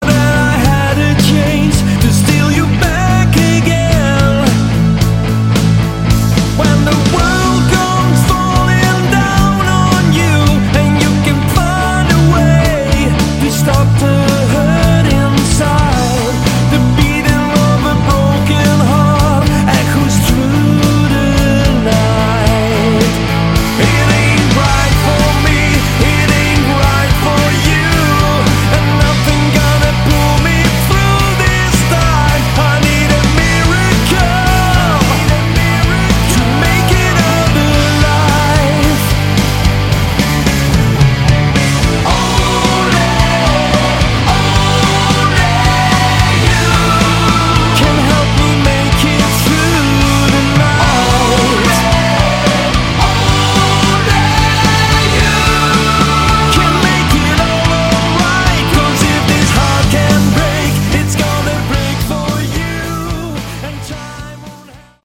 Category: AOR
guitars, keyboards
lead, rhythm, and bass guitar, backing vocals
lead and backing vocals
drums, backing vocals